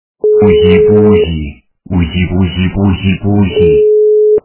» Звуки » Люди фразы » Уси-пуси... - уси-пуси-пуси
При прослушивании Уси-пуси... - уси-пуси-пуси качество понижено и присутствуют гудки.